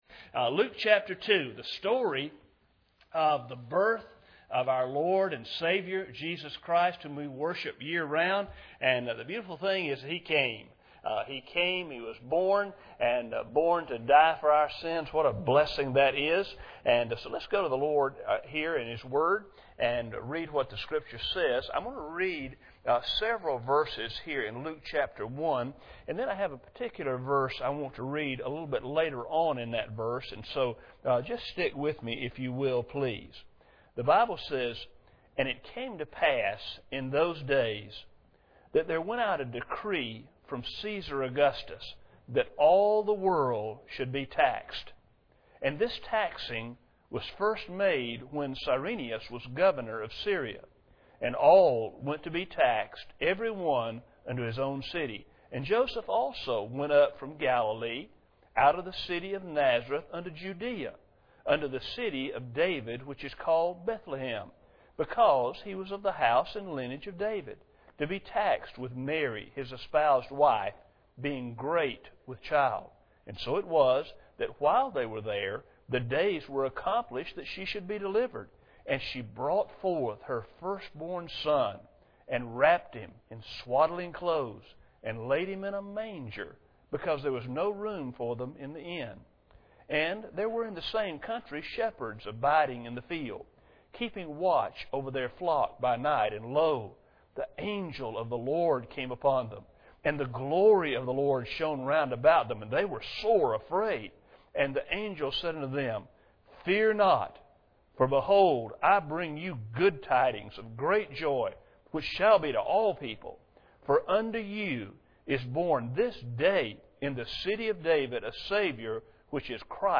Luke 2:1-52 Service Type: Sunday Morning Bible Text